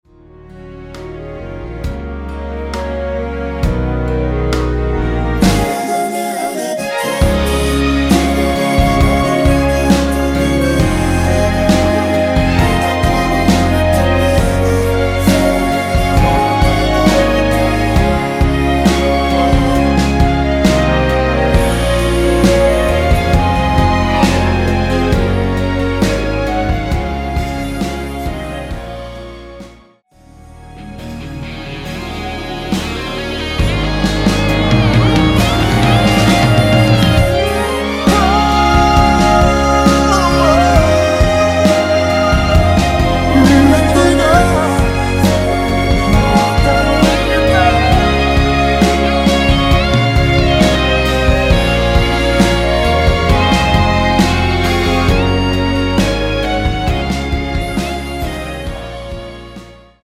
원키에서(-2)내린 멜로디와 코러스 포함된 MR입니다.(미리듣기 확인)
Db
앞부분30초, 뒷부분30초씩 편집해서 올려 드리고 있습니다.
중간에 음이 끈어지고 다시 나오는 이유는